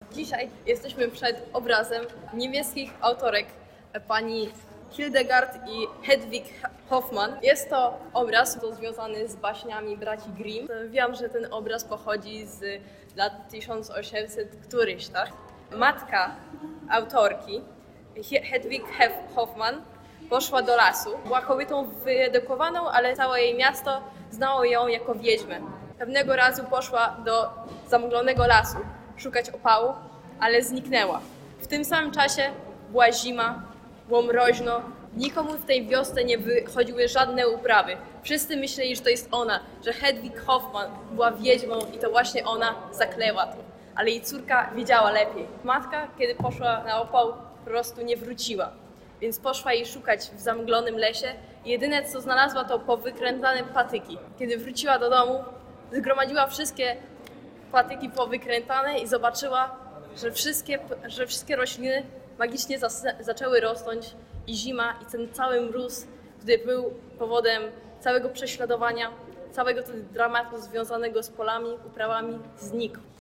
Moving Image. Intervention: Audioguide in Polish